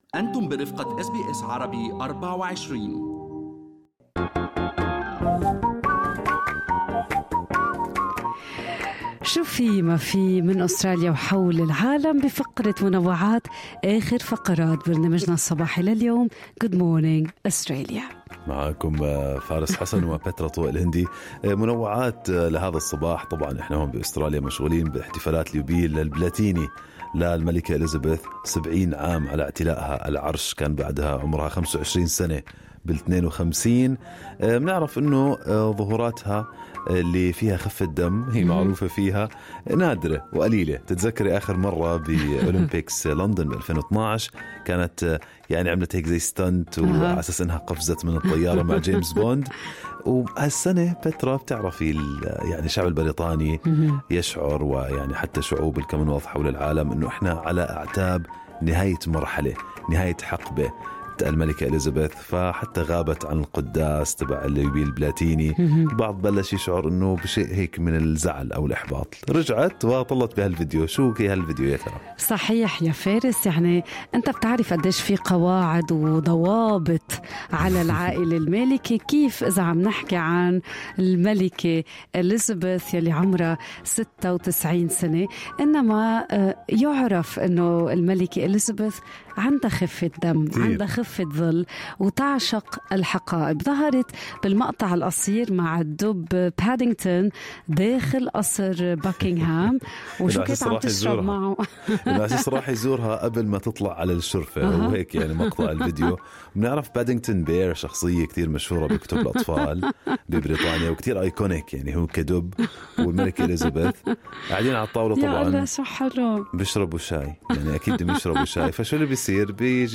نقدم لكم فقرة المنوعات من برنامج Good Morning Australia التي تحمل إليكم بعض الأخبار والمواضيع الخفيفة.